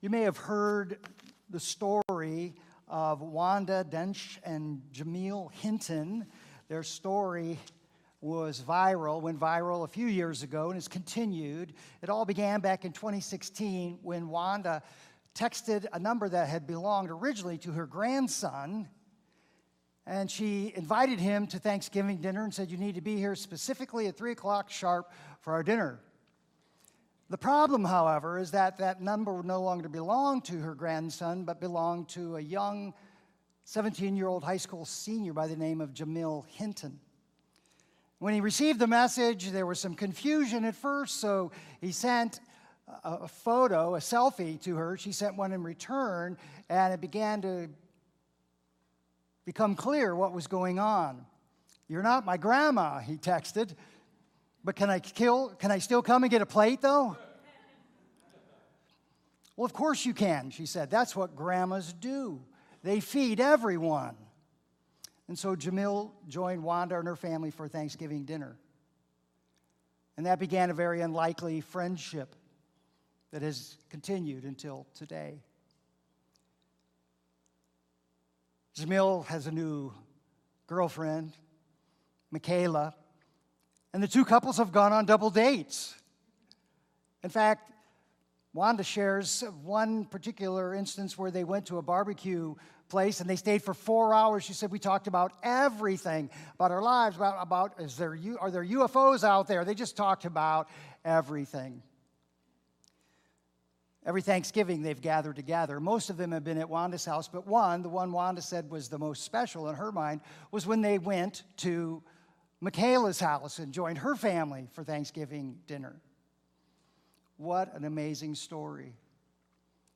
A message on radical hospitality